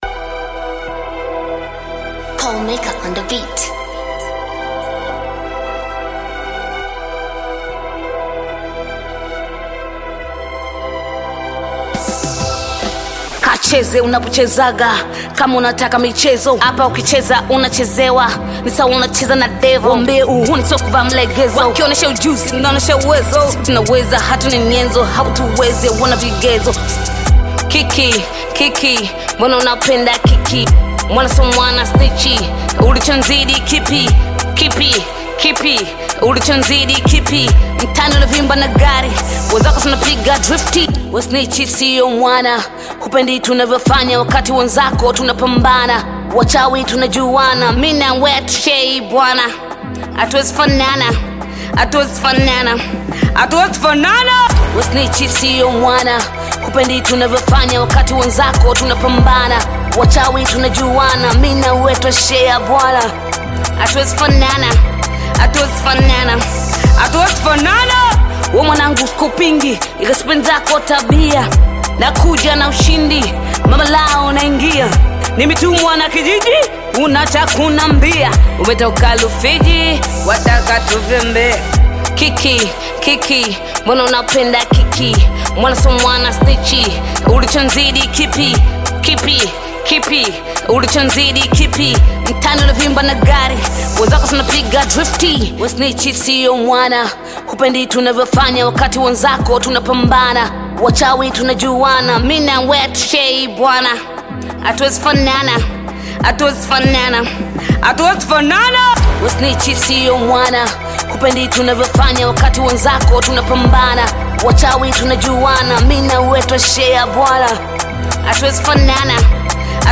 Bongo Flava music track
Tanzanian Bongo Flava artist, singer, and songwriter
Bongo Flava